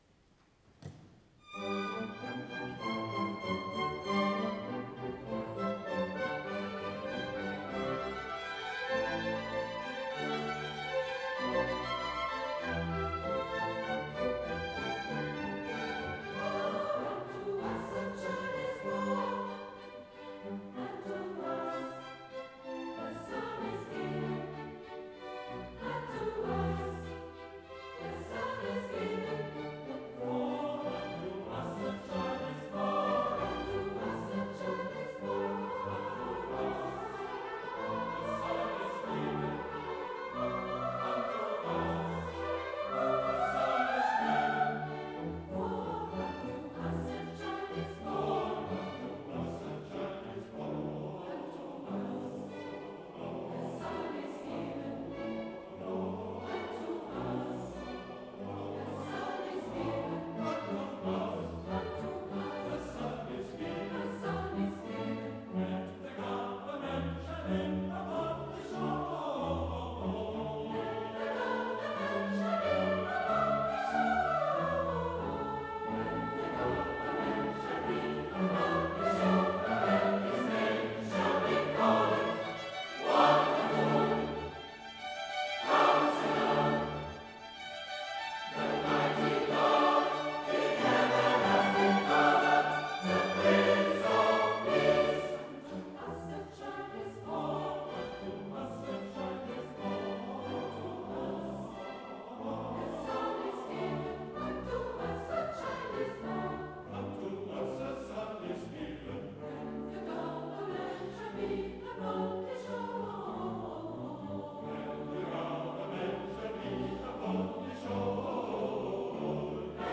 12 For unto us a child is born tiré de HAENDEL The Messiah – Concert à Aschaffenburg – 20/05/2023 par Aschaffenburger Kantorei et Choeur Saint-Germain.